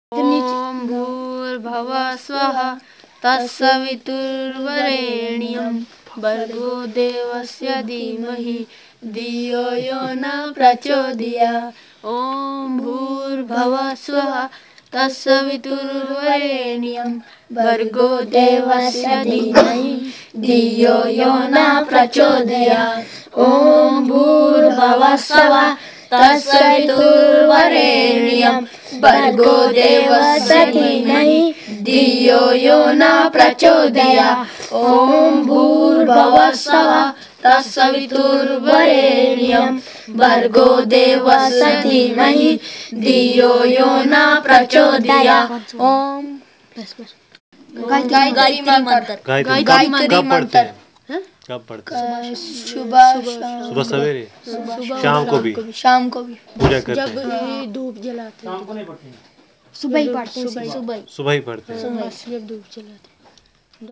Performance of devotional song